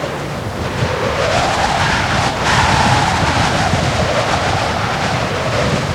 Звуки ветра. Sounds of wind.
Звук глухое завывание снежной бури.